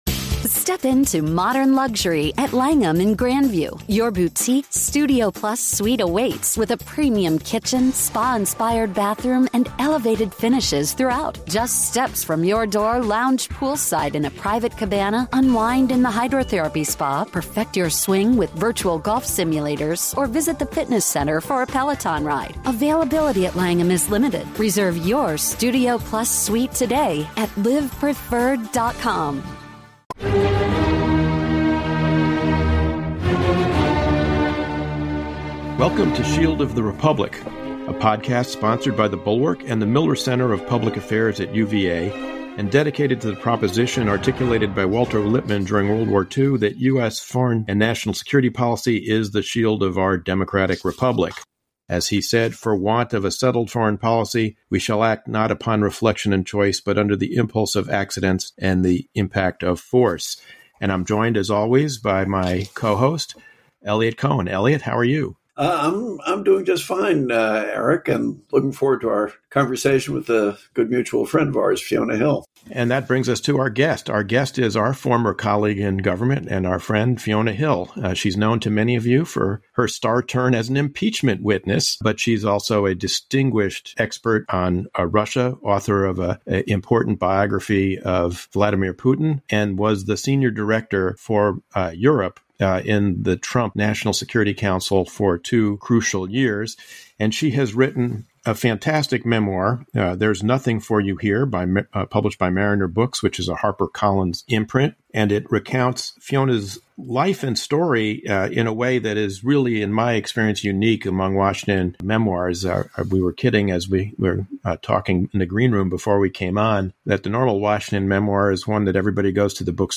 Former National Security Council Senior Director for Europe (and star impeachment witness) Fiona Hill is this week's guest discussing her book, There is Nothing For You Here, her service in the Trump Administration, the mortification of Trump's press conference with Putin at Helsinki (and transient thoughts of faking a seizure to make it stop) as well as the causes of and remedies for authoritarian populism.